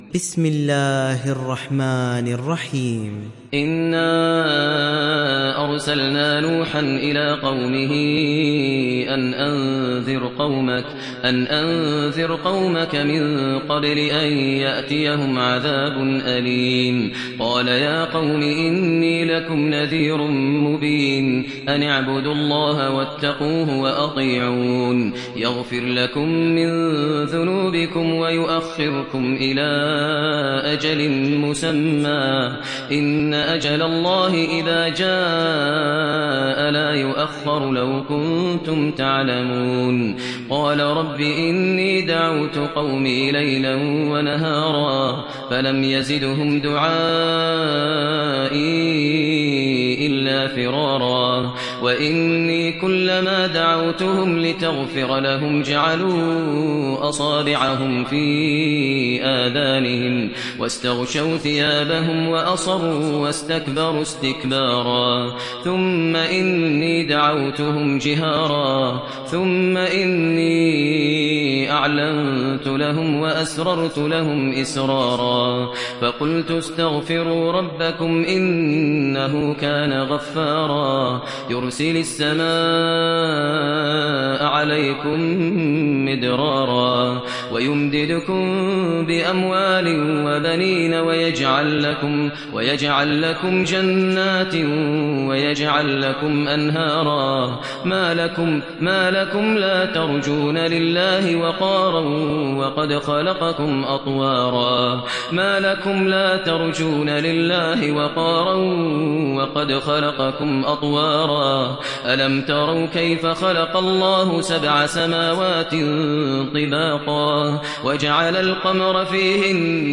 تحميل سورة نوح mp3 بصوت ماهر المعيقلي برواية حفص عن عاصم, تحميل استماع القرآن الكريم على الجوال mp3 كاملا بروابط مباشرة وسريعة